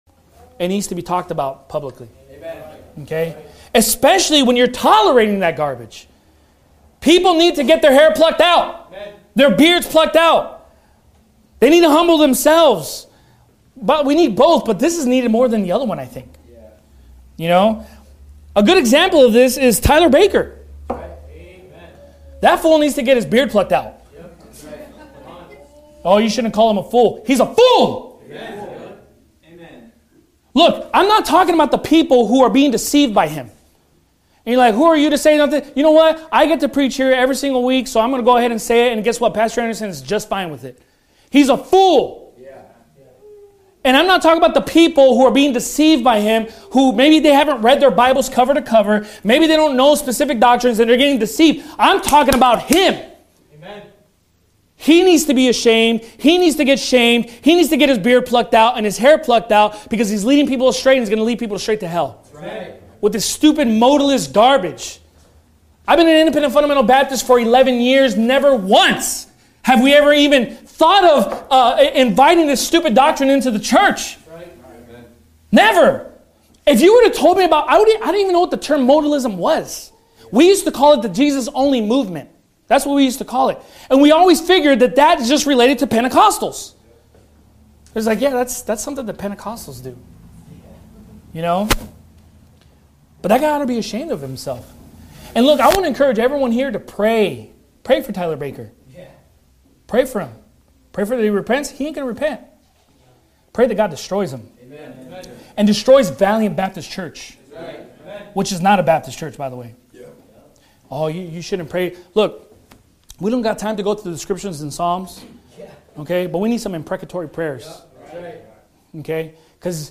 Sermon Clips